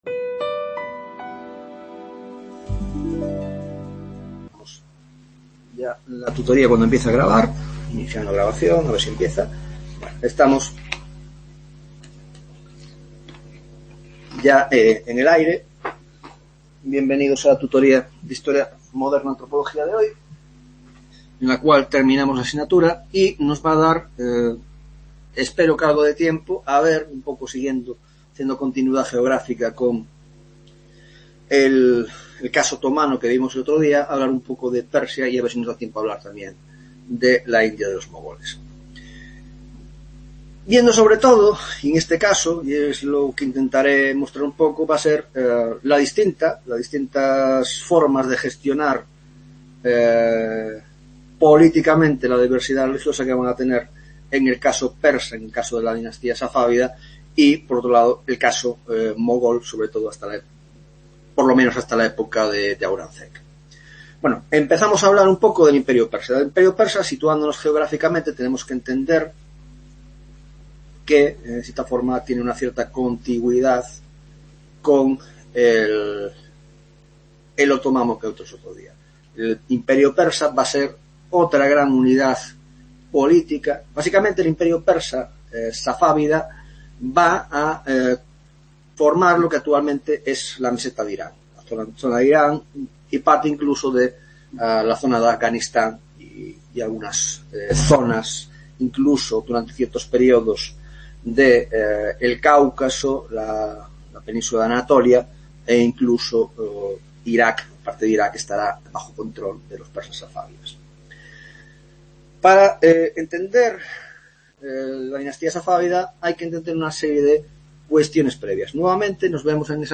10ª Tutoria de la asignatura de Historia Moderna, Grado de Antropología: Persia Safavida e India de los Mogoles